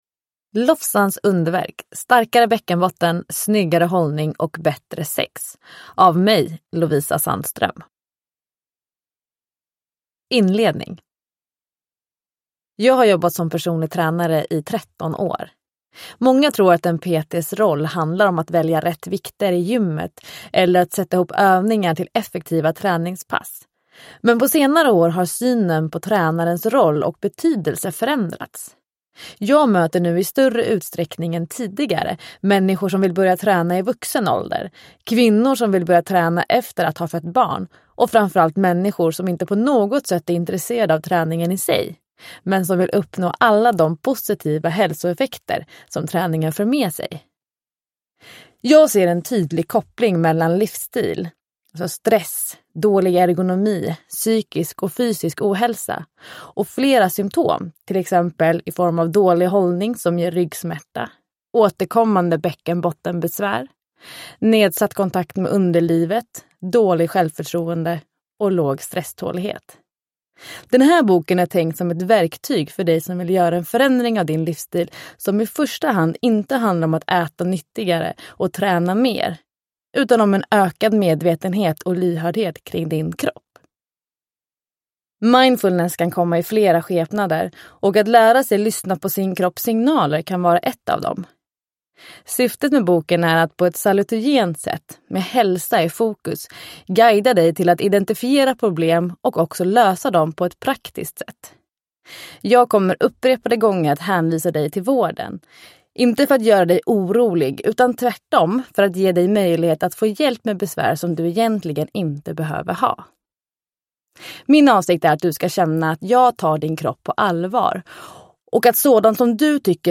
Lofsans underverk : starkare bäckenbotten, snyggare hållning och bättre sex – Ljudbok – Laddas ner
Uppläsare: Lovisa Sandström